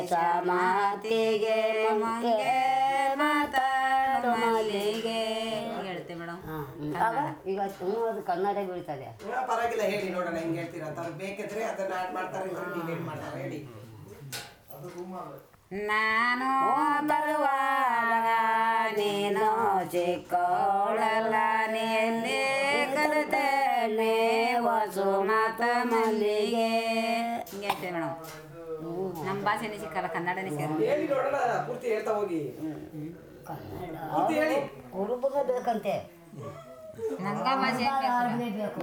Performance of a song about flowers